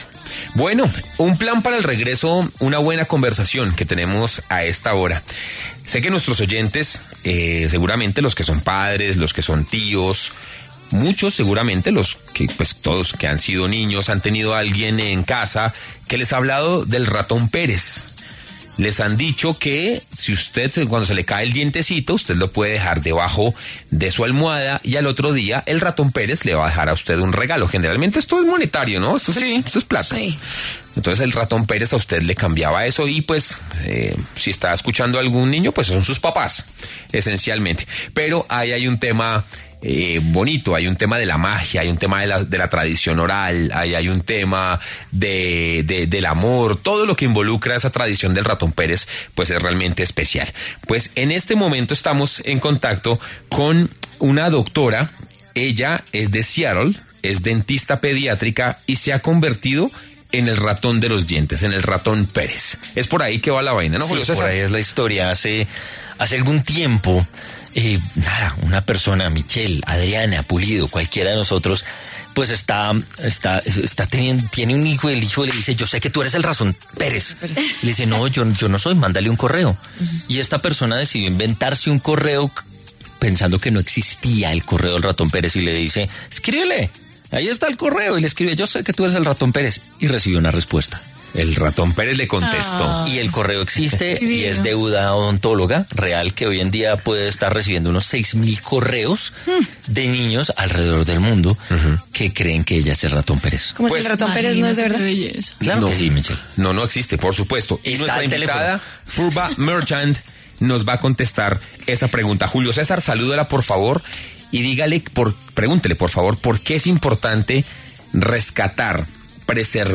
pasó por los micrófonos de La Hora del Regreso de W Radio para hablar de como se convirtió en este histórico personaje.